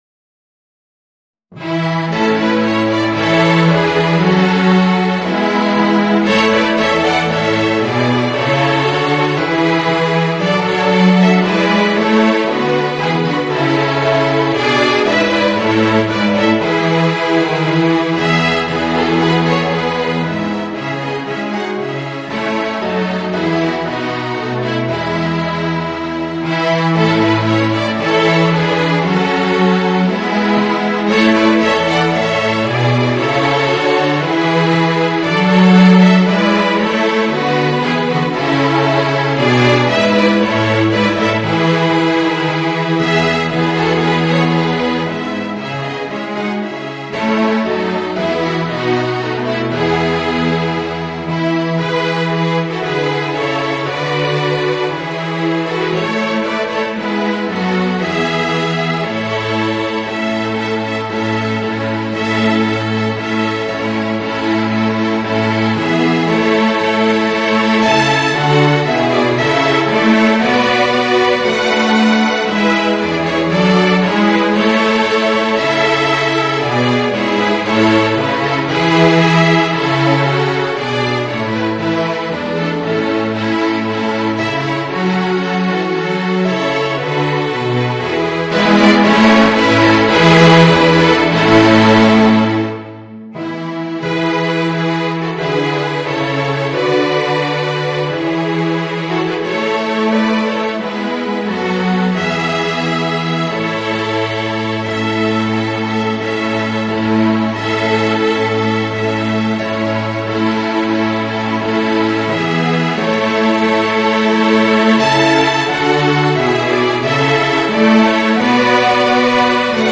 String Orchestra and Organ